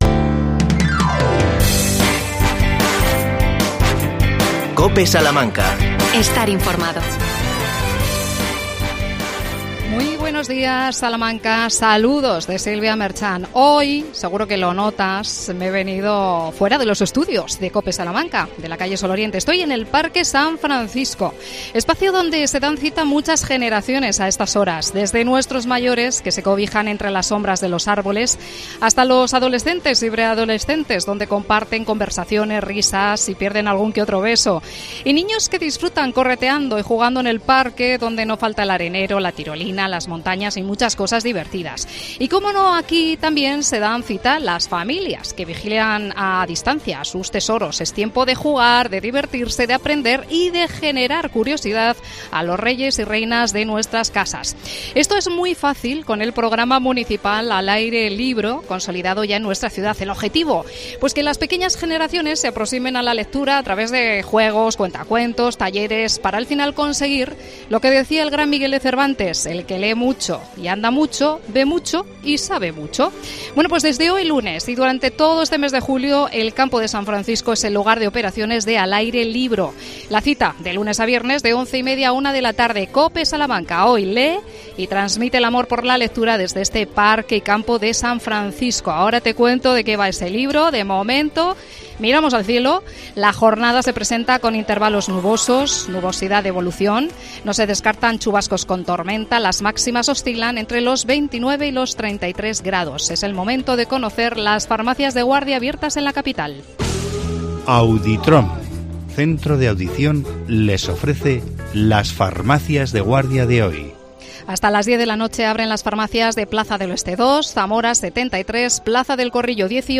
AUDIO: COPE SALAMANCA hoy en el Campo San Francisco en el inicio del Al Aire Libro.